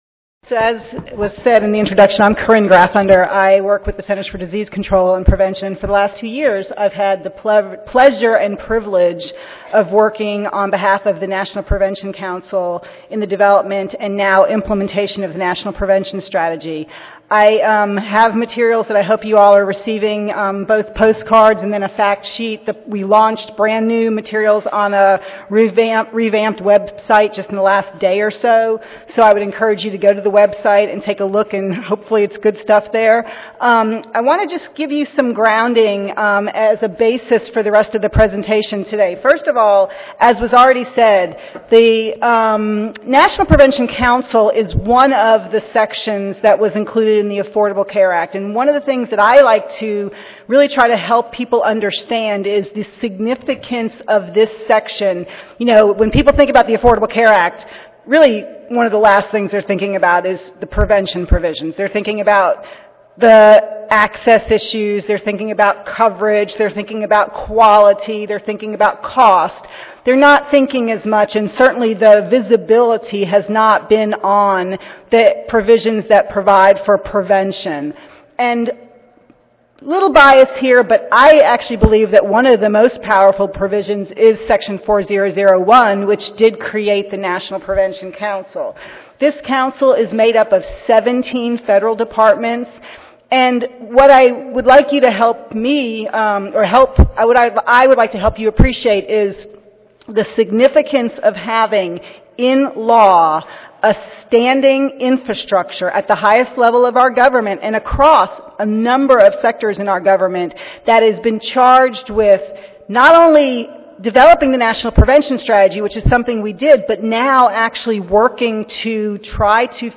The APHA Social Work Section will host an in-depth panel discussion on the NPS from the nation�s leading experts in social work, community health, practice, policy and Community Based Participatory Research (CBPR). Expert panelists will discuss and outline the goals of the NPS as related to core areas of social work.